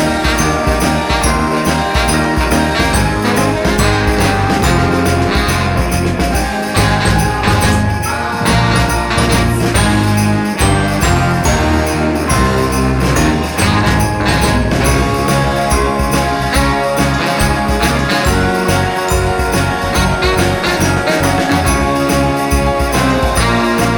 One Semitone Down Christmas 4:10 Buy £1.50